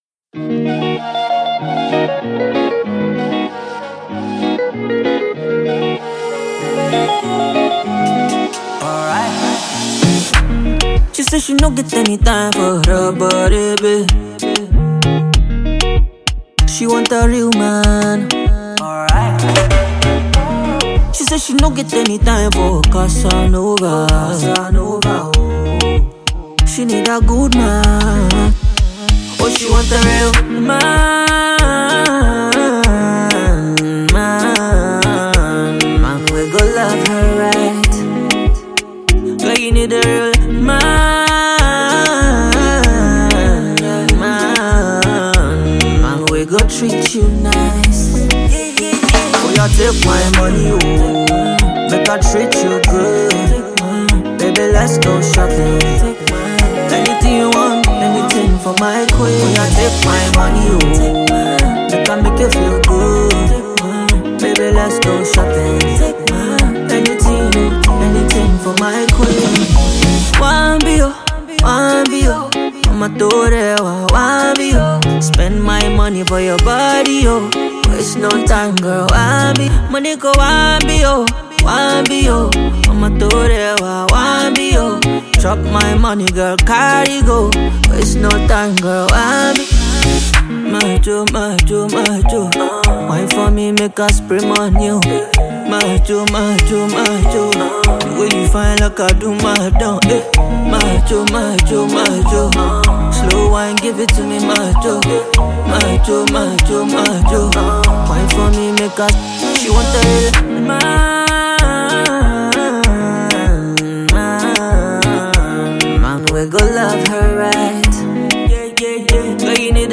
The nigerian r&b singer